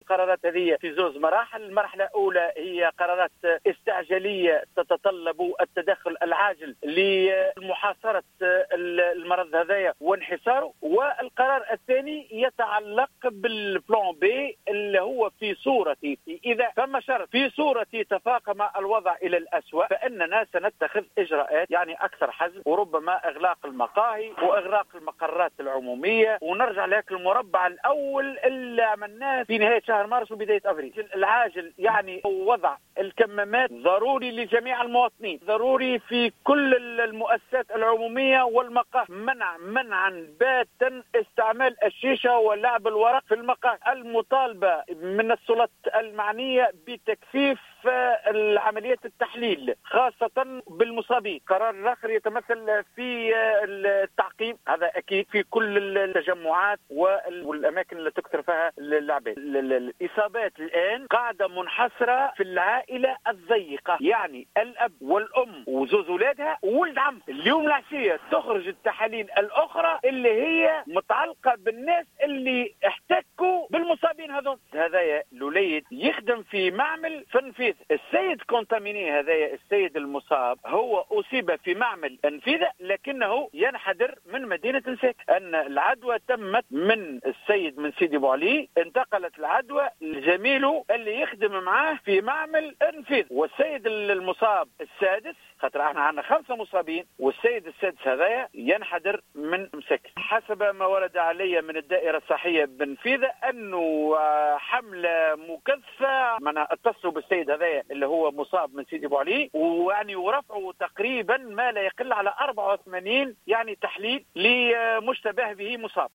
أكد رئيس المجلس البلدي بسيدي بوعلي إبراهيم بوبكر في تصريح لـ الجوهرة أف أم أنه...